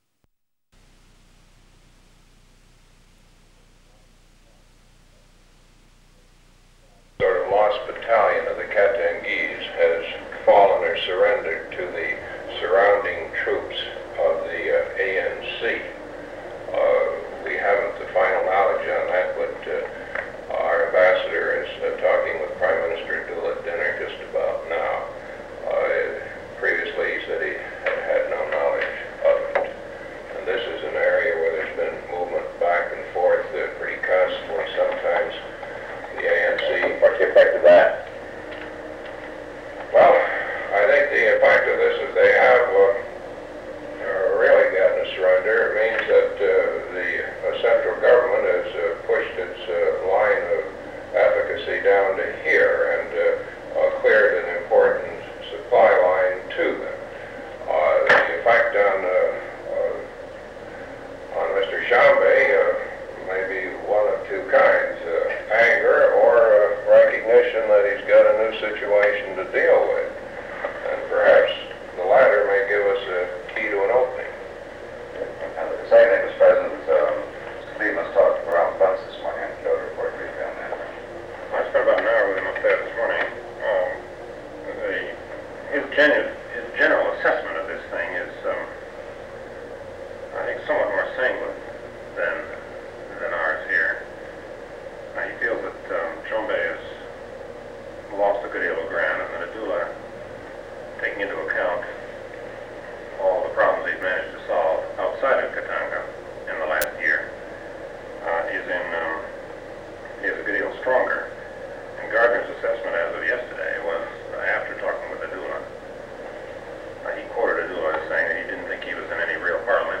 Secret White House Tapes | John F. Kennedy Presidency Meeting on the Congo Rewind 10 seconds Play/Pause Fast-forward 10 seconds 0:00 Download audio Previous Meetings: Tape 121/A57.